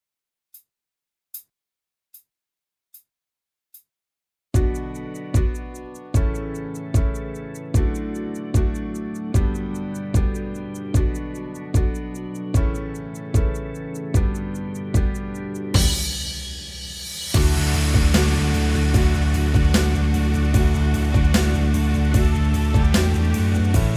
Minus Acoustic Guitars Pop (2010s) 4:21 Buy £1.50